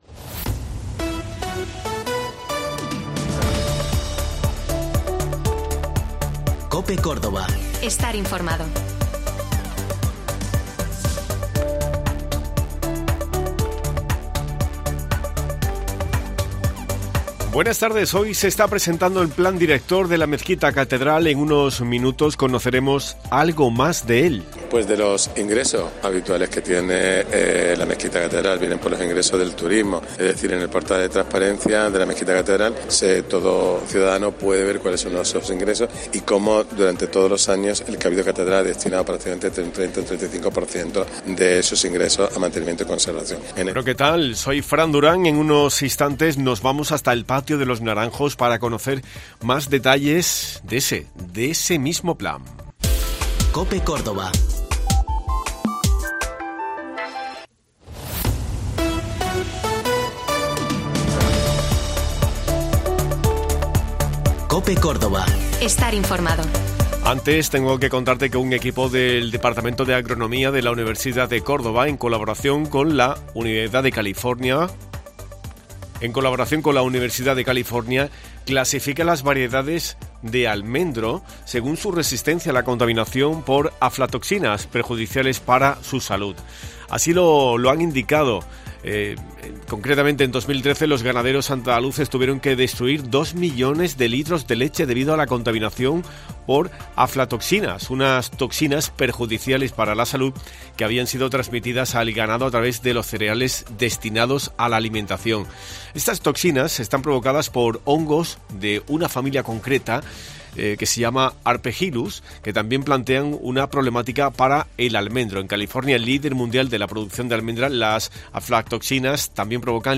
En esta mañana hemos conocido el Plan Director de la Mezquita-Catedral de Córdoba. Hemos estado en el Patio de los Naranjos para conocer todos los detalles.